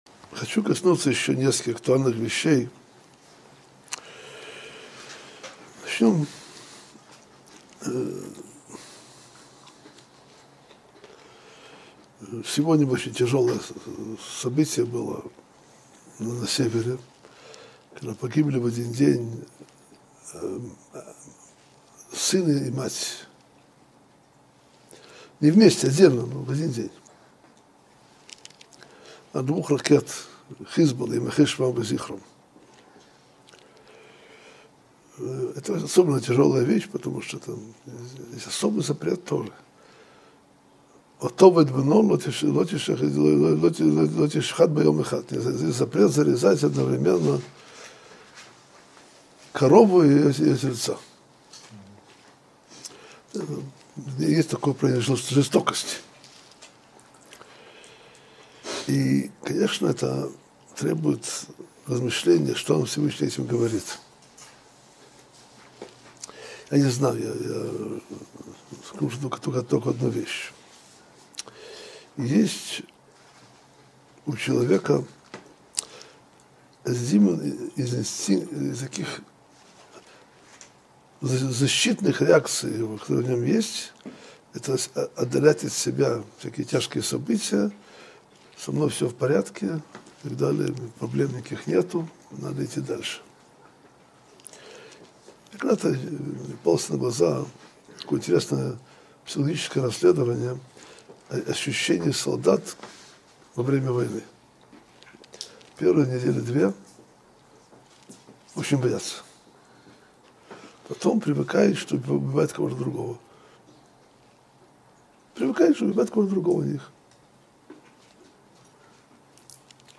Содержание урока: Гибель на севере Израиля сына и матери. Ощущение солдат во время войны. Почему солдаты на войне не боятся?